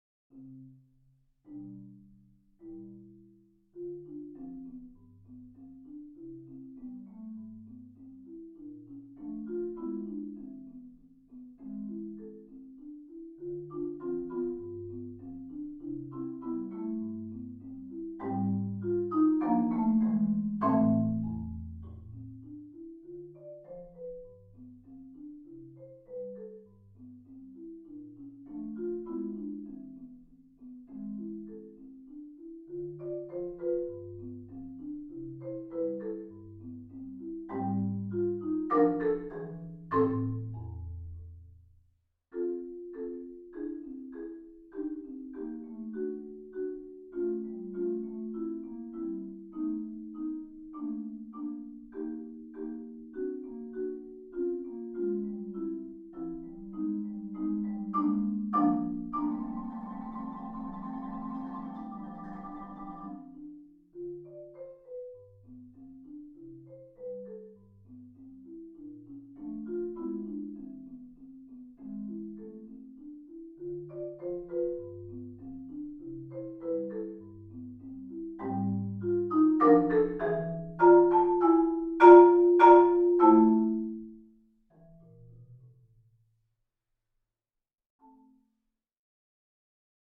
Genre: Marimba (4-mallet)
Marimba (5-octave)
An elegant new setting of a lovely English folk song.